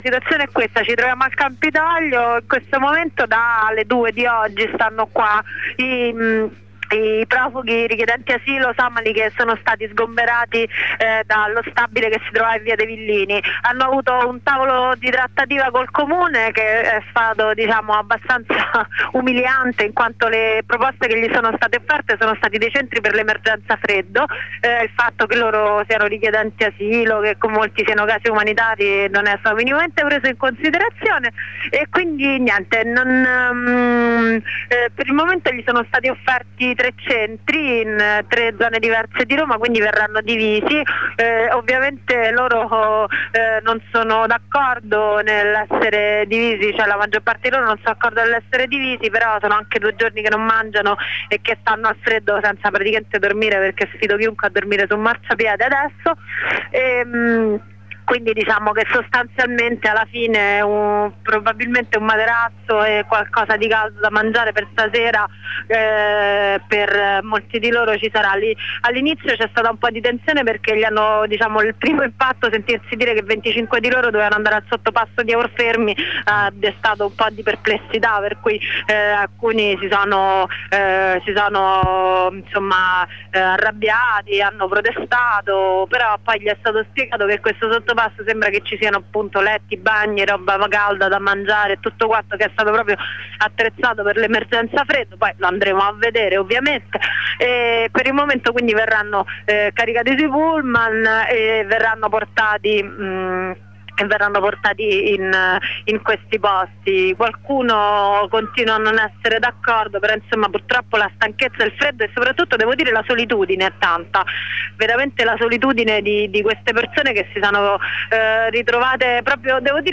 Ascolta la corrispondenza con una compagna della redazione.